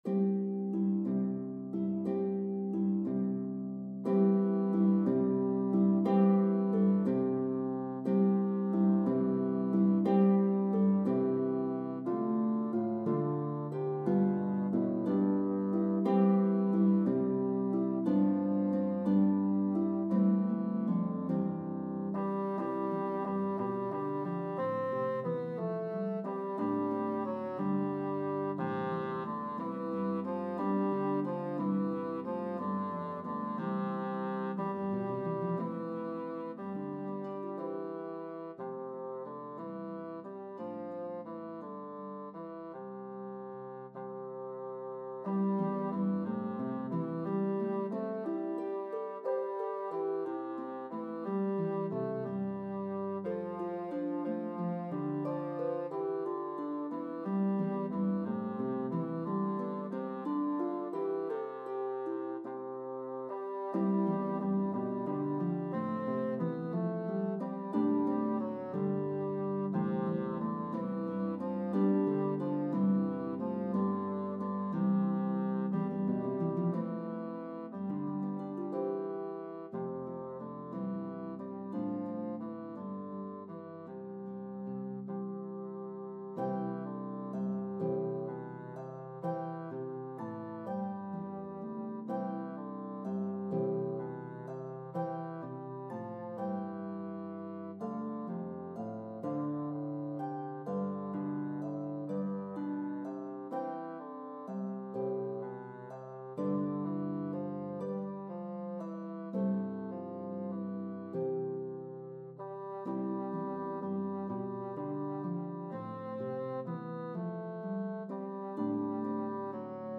traditional Christmas hymn
Harp and Bassoon version